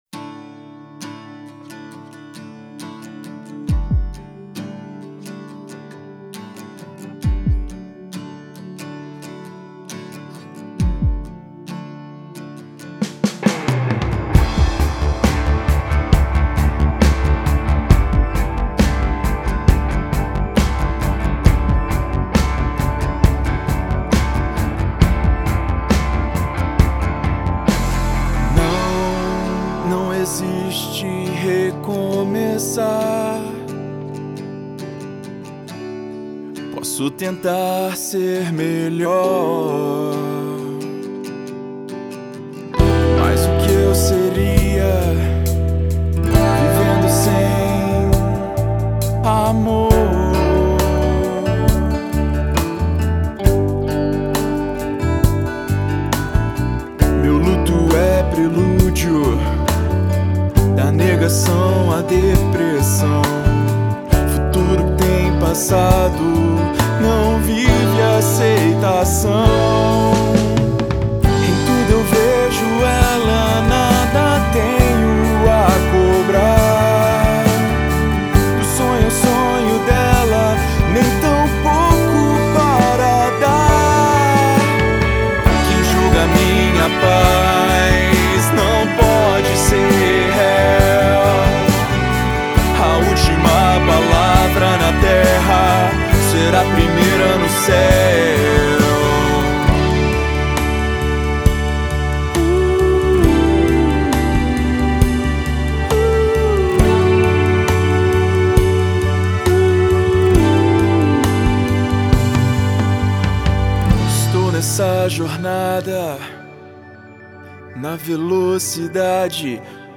Brazilian Alternative Rock